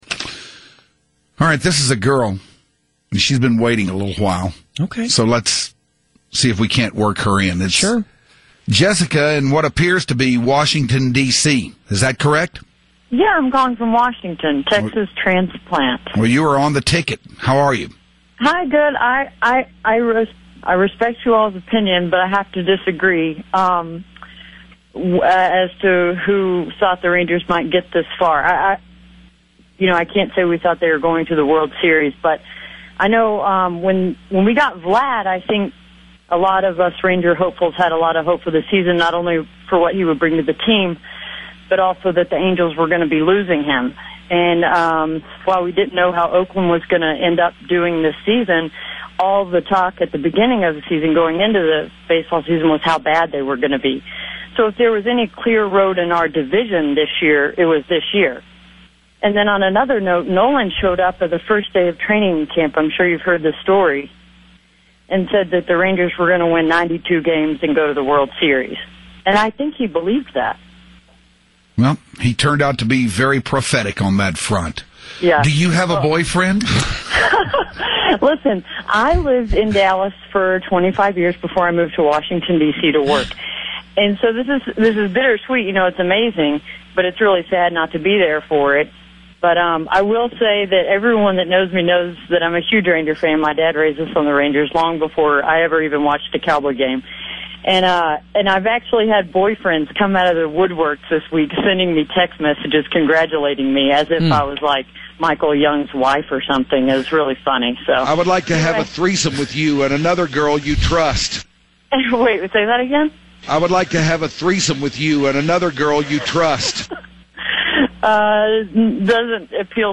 a Female Caller
It's always a pleasure whenever a woman musters up the courage to call in to the Hardline, but she better be armed with a razor sharp wit, a lightning-quick sense of humor and a set of massive fake jugs.